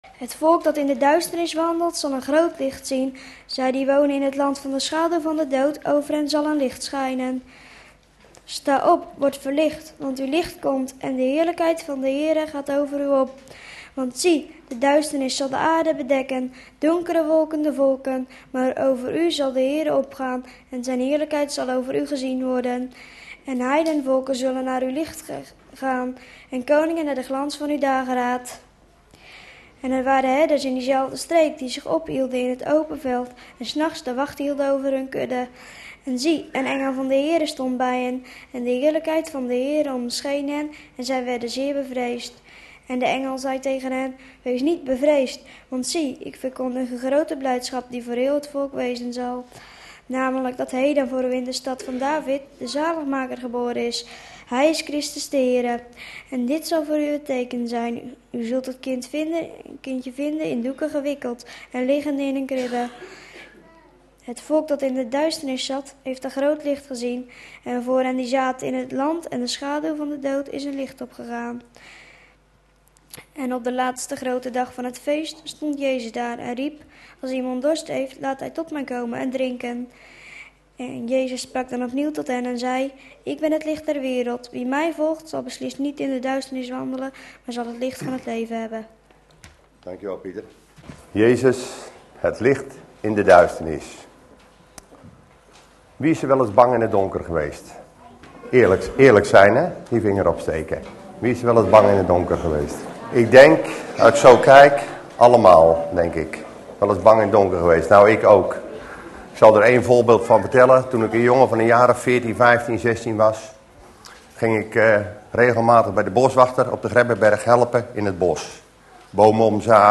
In de preek aangehaalde bijbelteksten